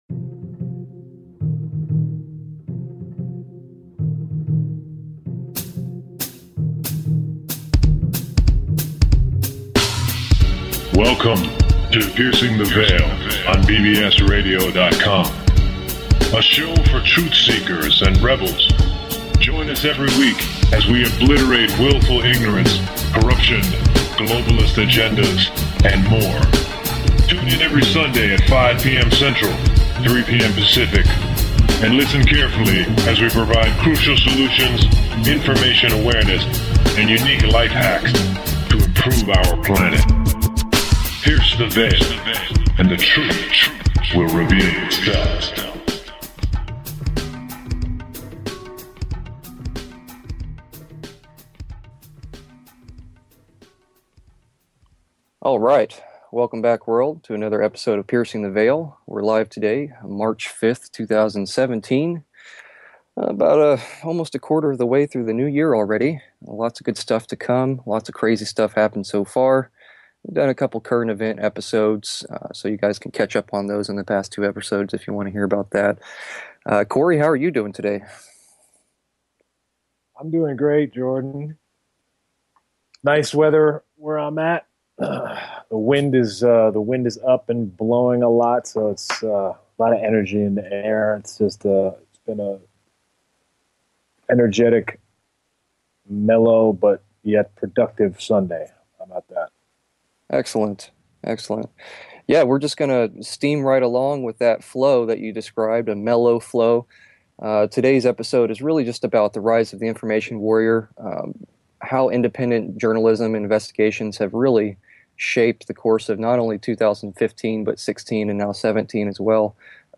Talk Show Episode, Audio Podcast, Show 48- Rise Of The Information Warrior and A Tribute to Freedom of Speech, Information, and Current Events on , show guests , about Piercing The Veil, categorized as Health & Lifestyle,Kids & Family,Psychology,Self Help,Society and Culture,Spiritual
Later in the show, A lively conversation ensues regarding the importance of Information Awareness and the Freedom to Disseminate Truth without Interference.